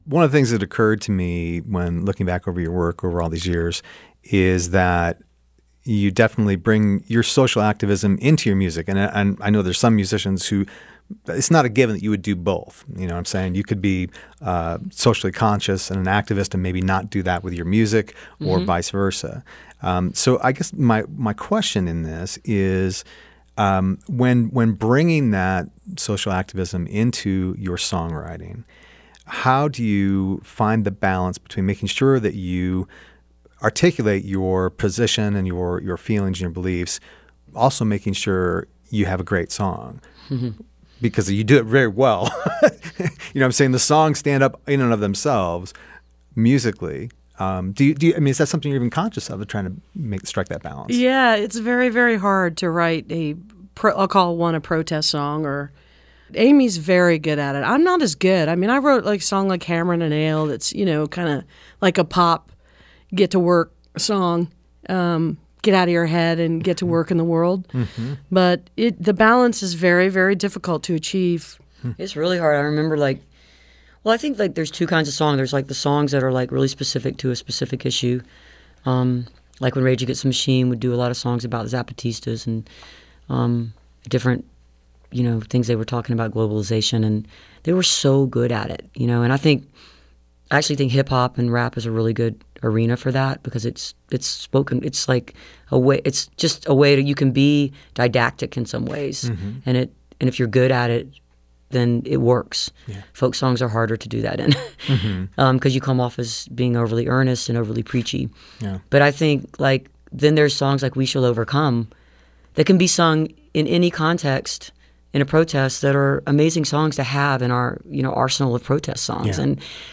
(recorded from webcast)
04. interview (4:53)